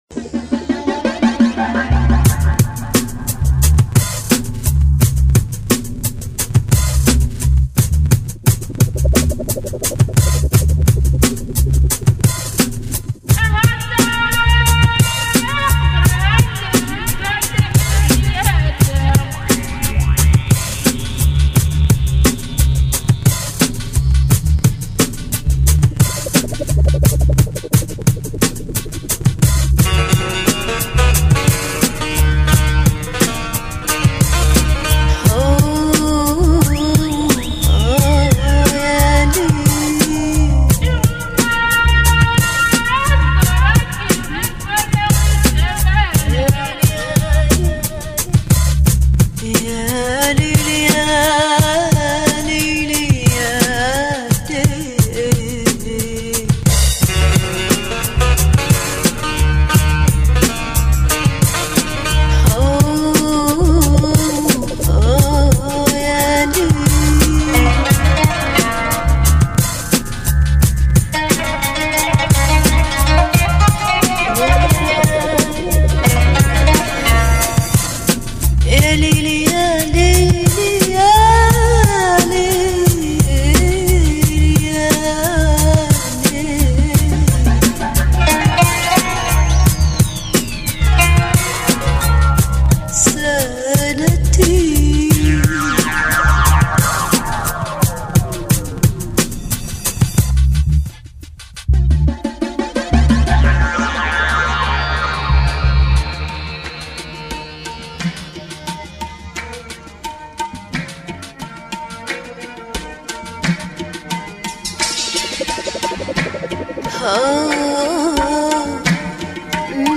甜美中氤蕴迷幻，舒适解压的聆听旅程。
Bossa Nova，Downtempo，Nu Jazz 与 Lounge